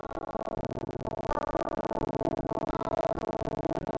Getting distortion on audio recording - guitar and vocal
Incidentally when I had just opened Audacity I managed to make a quick audio recording (guitar) that sounded fine, but as soon as I tried to use the onboard click track the next attempt to record came out “distorted” again; then when I switched the clicktrack off again it was still distorting…
but there are numerous dropouts in the track which is why it sounds incorrect.
It’s not distortion - it’s severe dropouts.